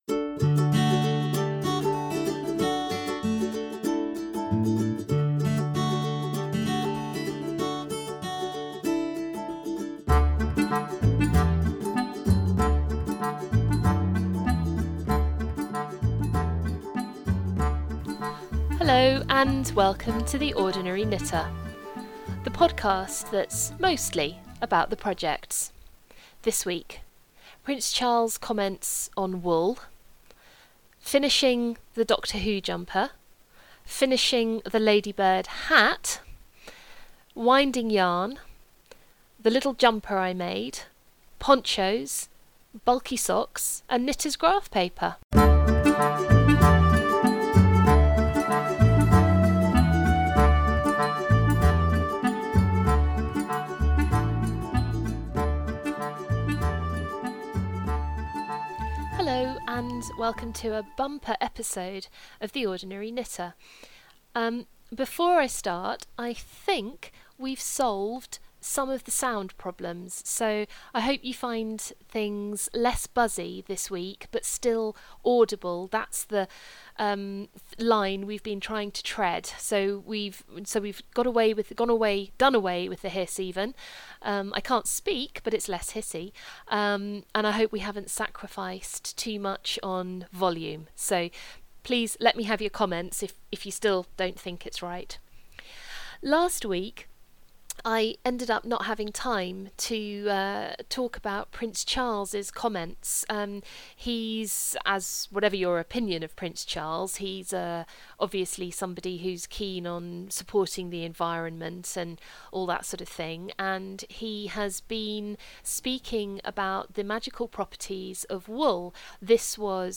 Welcome to The Ordinary Knitter, a knitting podcast discussing projects, yarns and patterns.